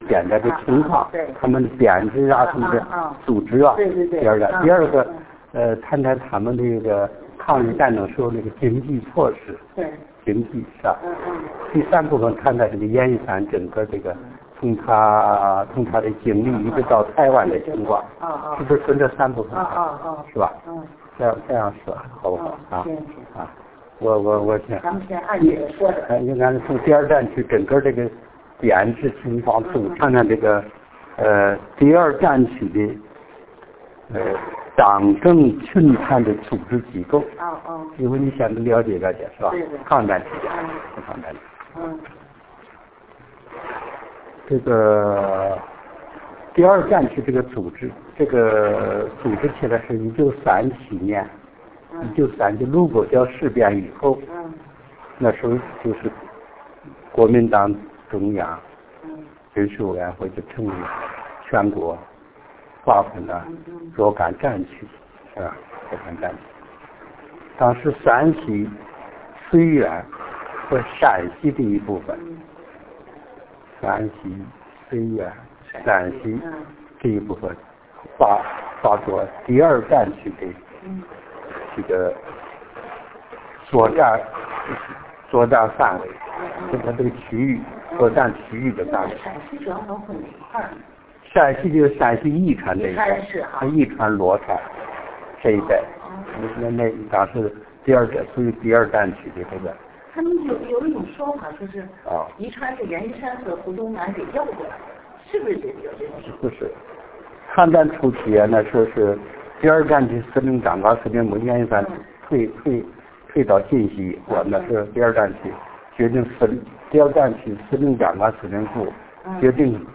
被采访人：赵雨亭1